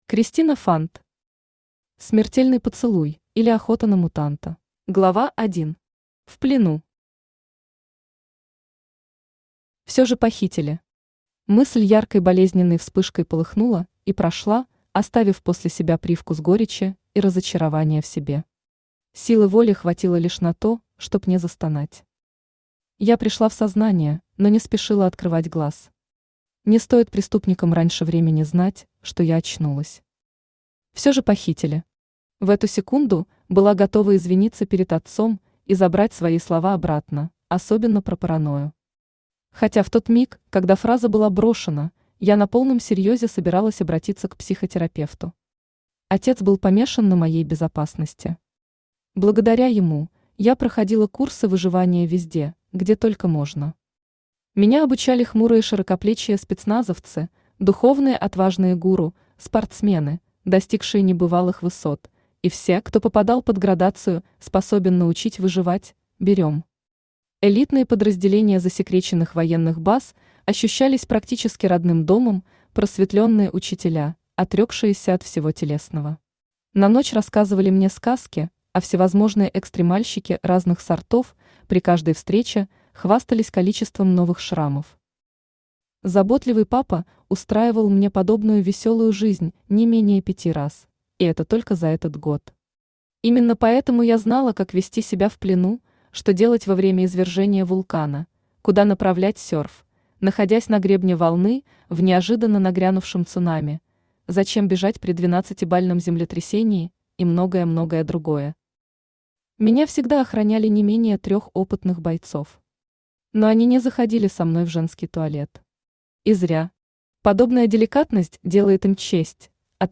Аудиокнига Смертельный поцелуй, или Охота на мутанта | Библиотека аудиокниг
Aудиокнига Смертельный поцелуй, или Охота на мутанта Автор Кристина Фант Читает аудиокнигу Авточтец ЛитРес.